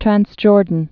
(trănsjôrdn, trănz-)